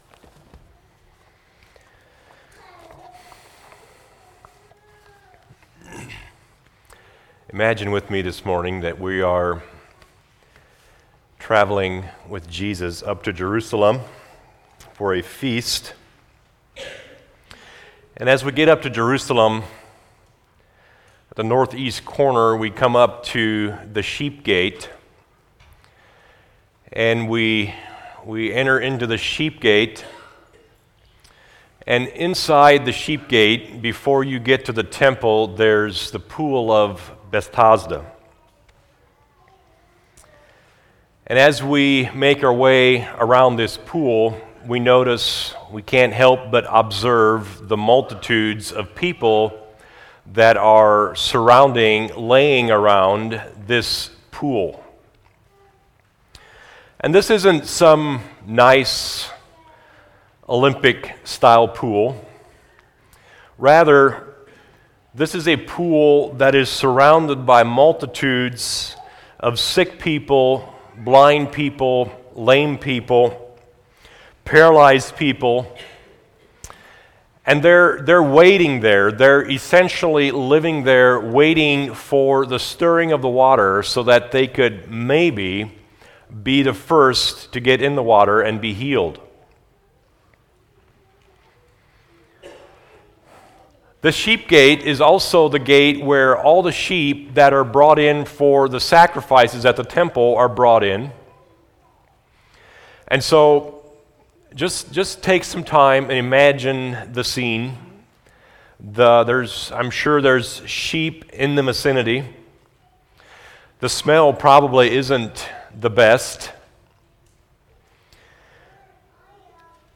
Home Sermons How Should I Read the Bible How Did The NT Writers Use The OT?